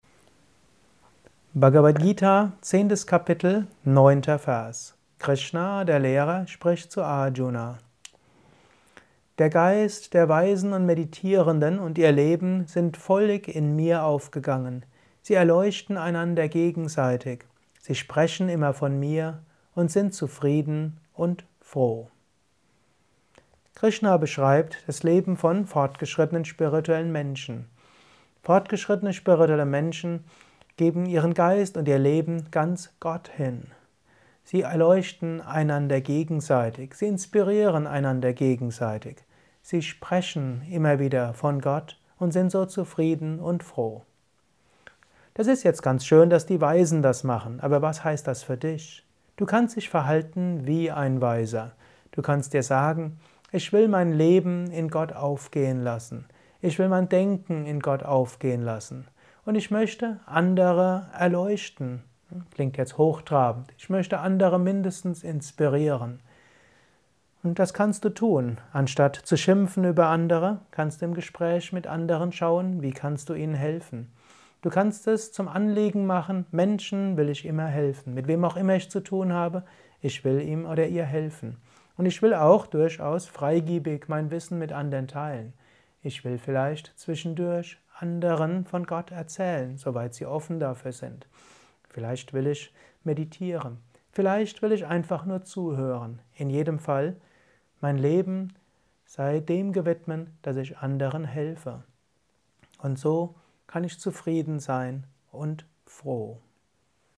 Dies ist ein kurzer Kommentar